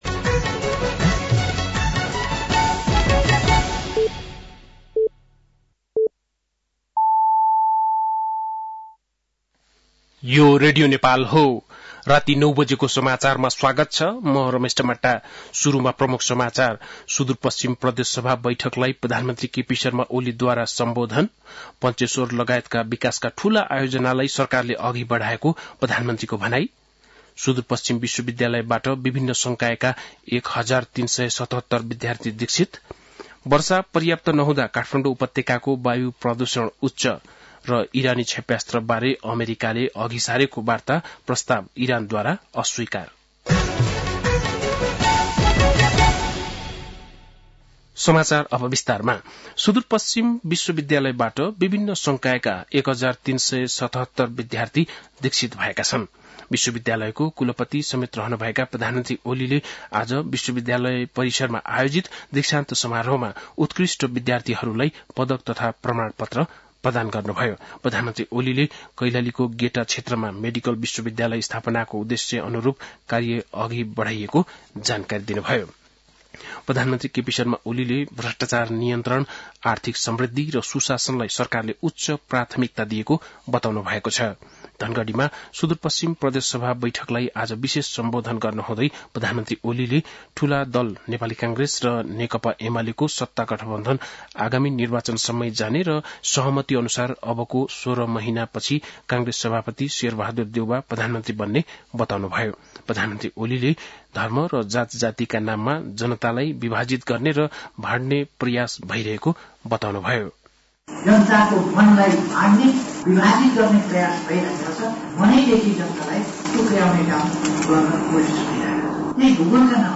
बेलुकी ९ बजेको नेपाली समाचार : २६ फागुन , २०८१
9-pm-news.mp3